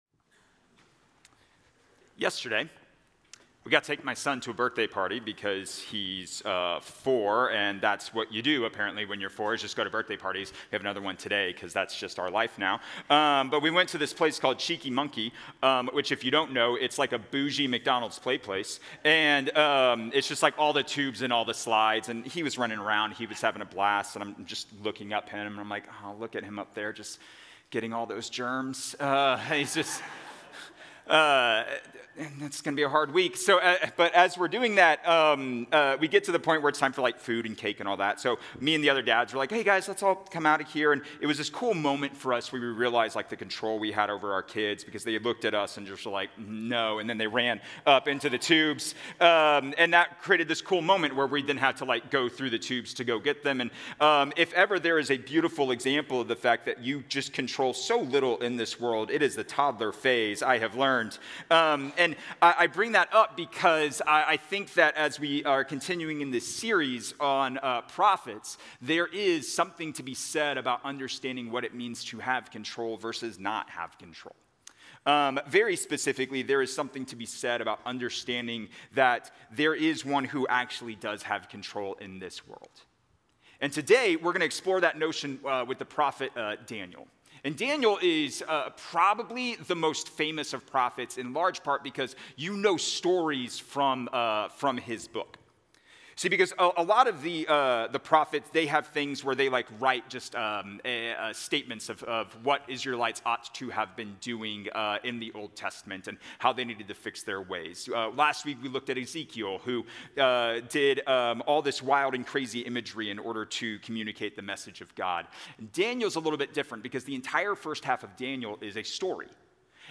A message from the series "Parables."